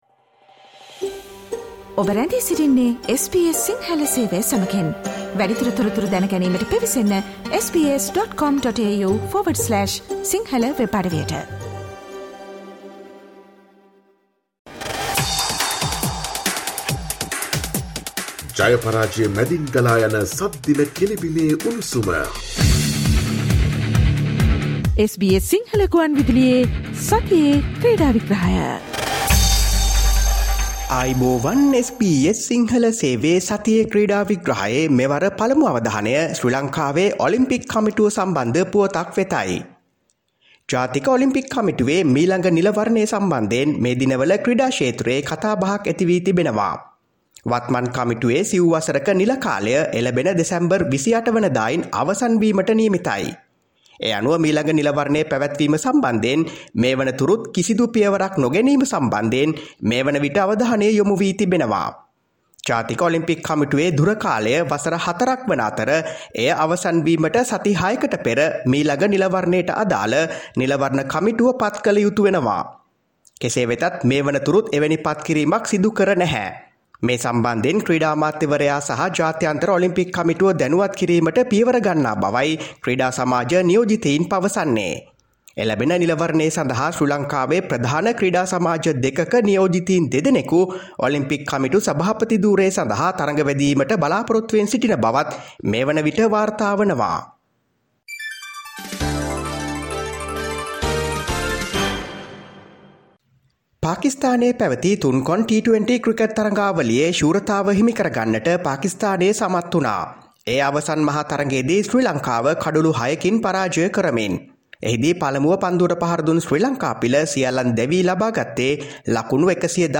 පාකිස්තාන තුන්කොන් තරගාවලියේ දී ලැබූ අත්දැකීම් දසුන් ශානක කතා කරයි